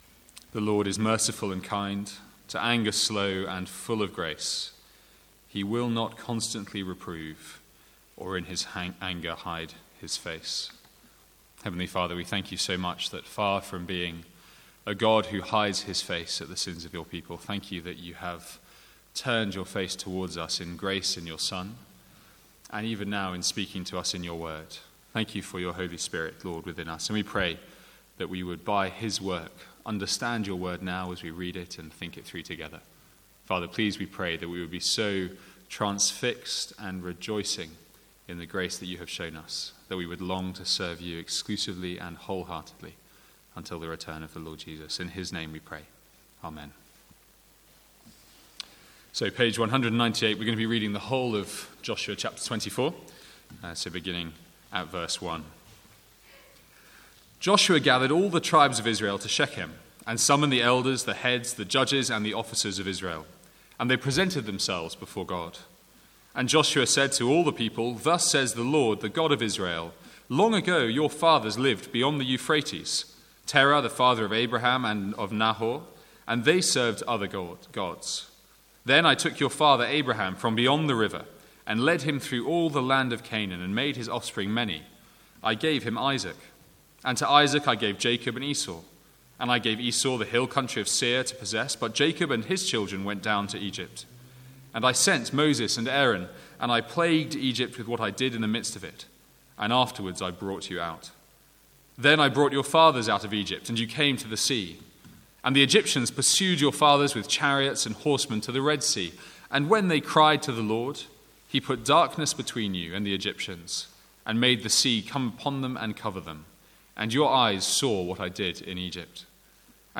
Sermons | St Andrews Free Church
From the Sunday evening series in Joshua.